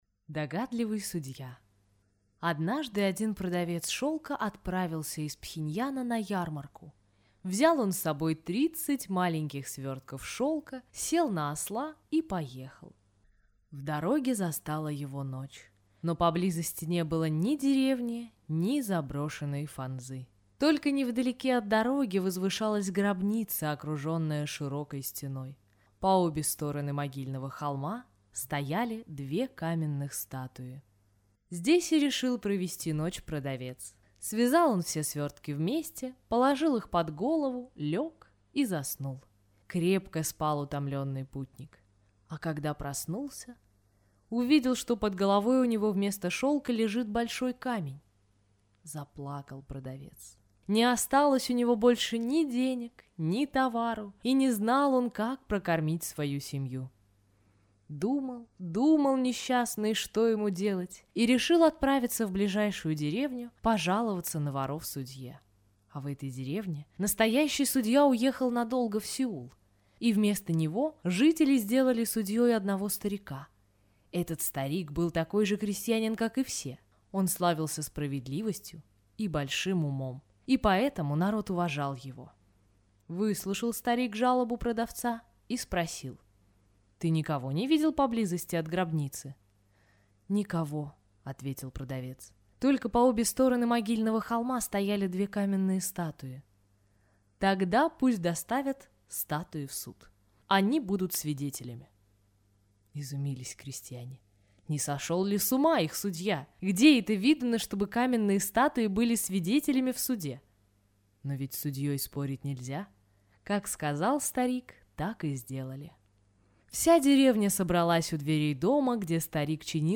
Догадливый судья – корейская аудиосказка